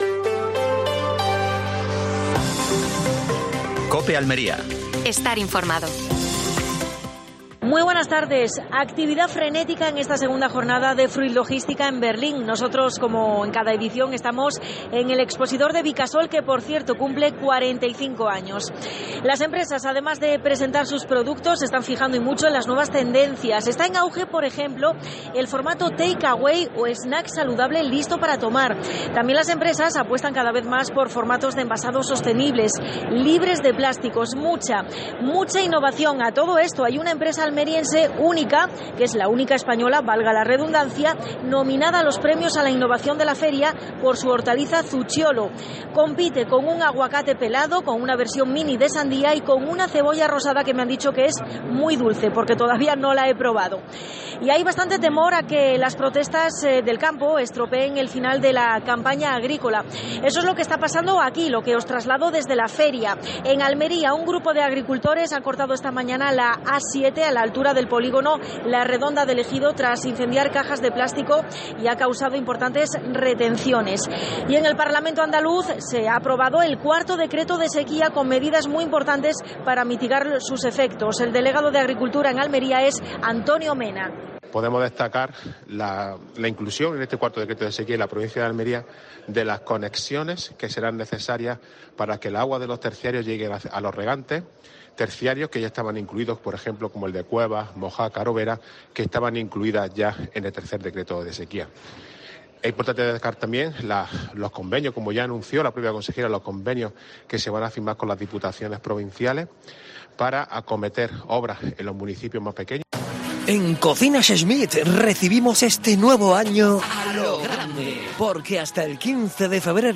AUDIO: Última hora en Almería. Fruit Logística desde Berlín. Entrevista a Carlos Sánchez (diputado provincial).